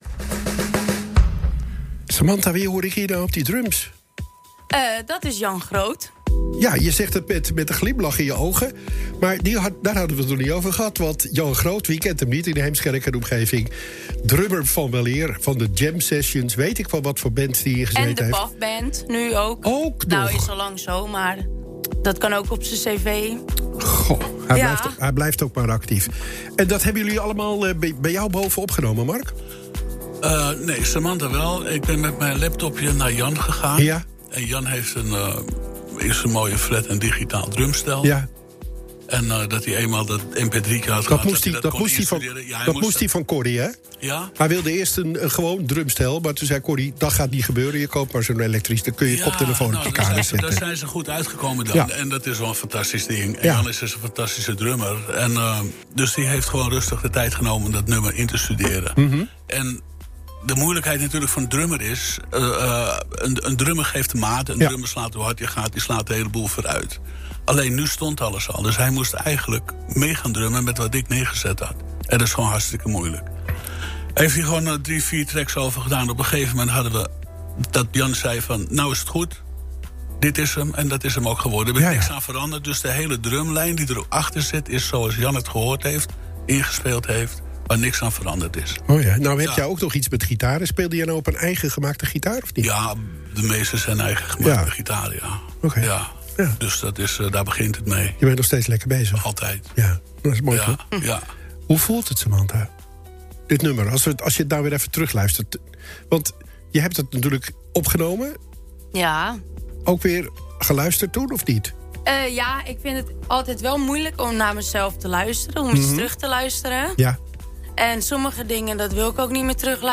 Onderstaand eerst de muziektrack en daaronder deel 2 van het gesprekje.